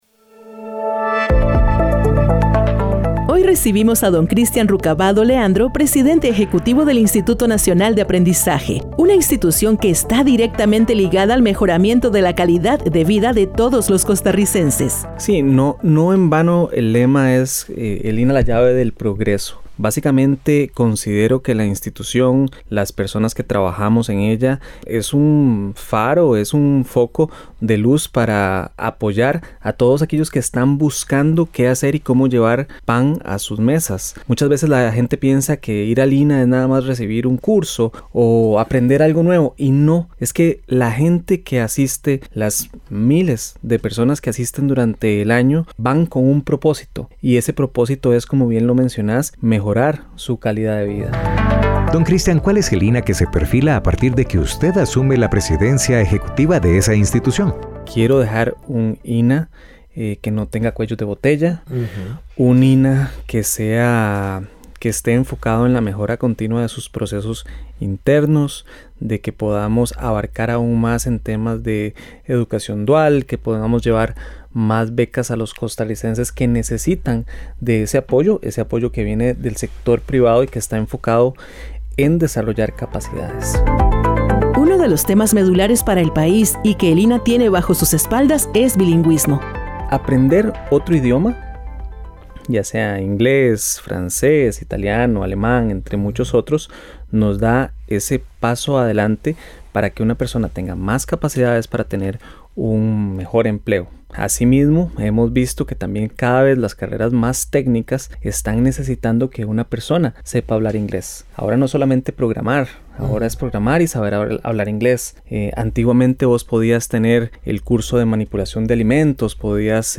Entrevista a Christian Rucavado, presidente ejecutivo del INA